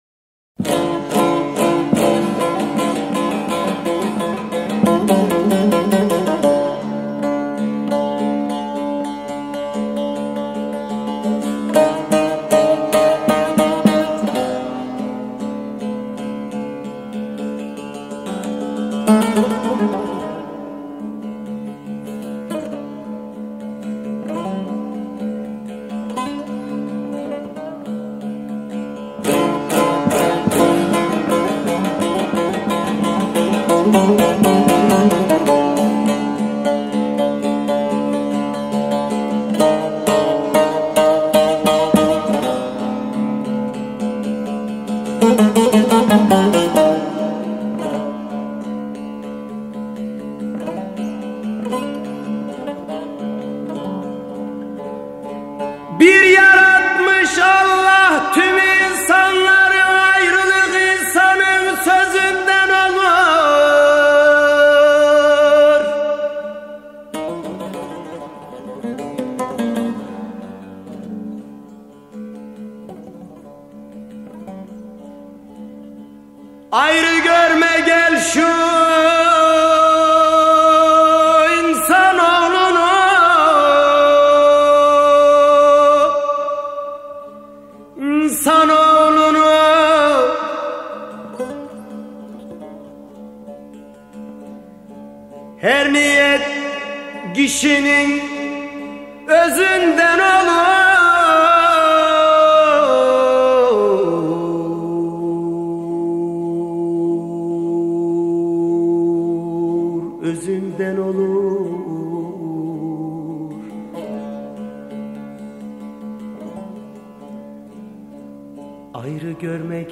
Bozlaklar insan feryadını çığlığınıandıran müzikal haykırışlardır.
Bozlaklar genel olarak minör karakterli ezgi yapısına sahiptir.
Örnek Bozlak: Bir Yaratmış Allah
Neşet Ertaş'tan unutulmaz bir Kırşehir bozlağı örneği.